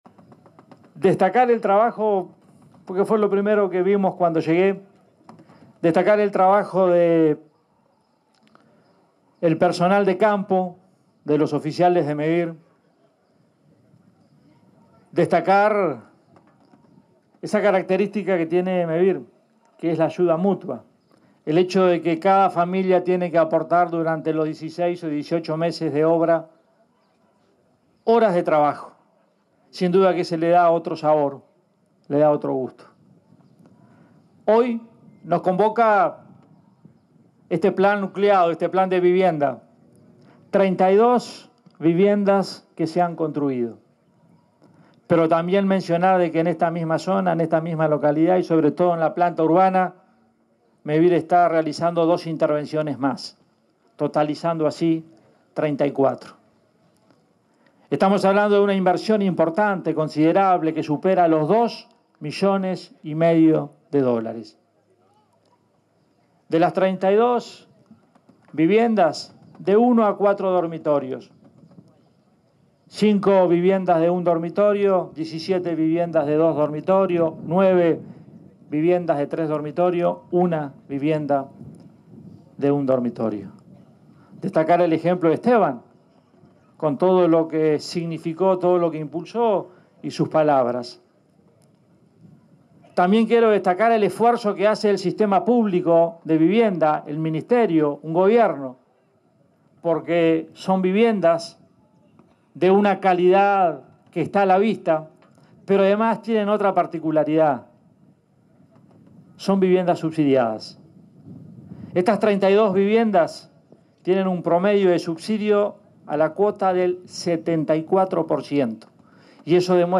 Palabras del presidente de Mevir, Andrés Lima
Palabras del presidente de Mevir, Andrés Lima 28/11/2025 Compartir Facebook X Copiar enlace WhatsApp LinkedIn En oportunidad de la entrega de viviendas por parte de Mevir, en la localidad de Castellanos, departamento de Canelones, el presidente del organismo, Andrés Lima, se expresó en acto de inauguración.